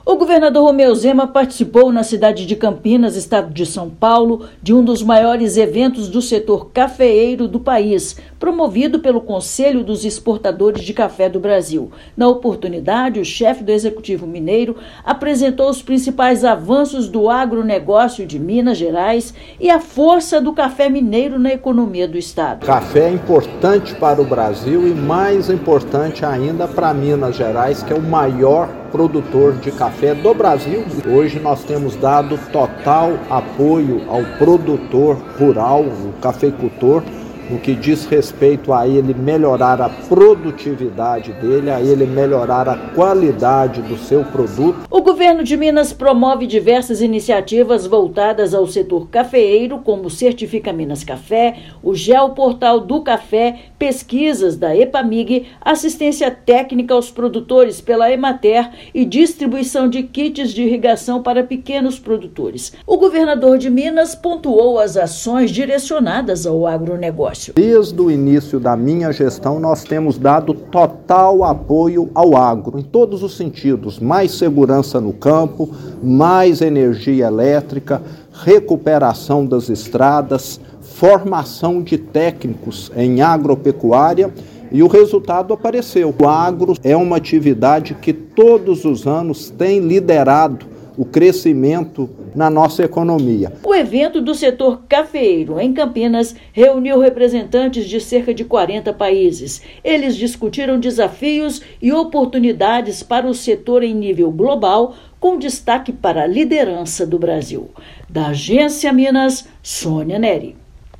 Coffee Dinner & Summit reuniu produtores e exportadores de café de 40 países, que debateram sobre o protagonismo do setor no país. Ouça matéria de rádio.